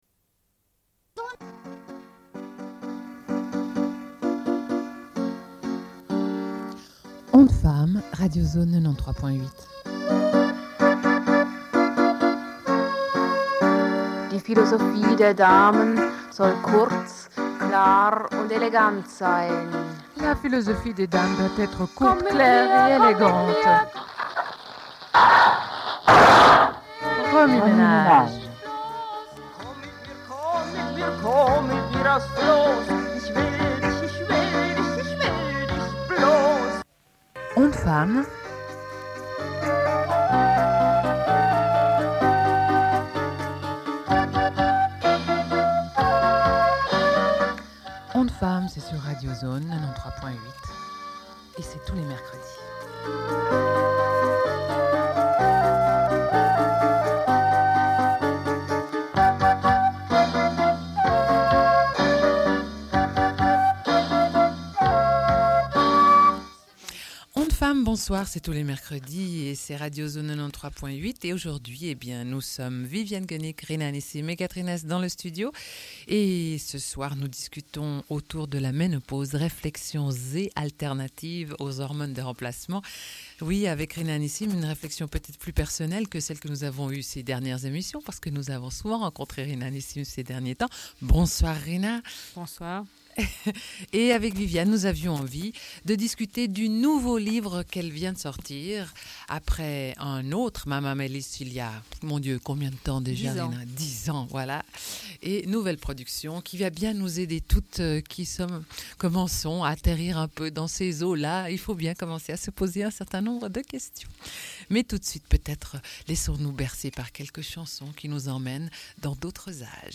Une cassette audio, face A31:44
Radio Enregistrement sonore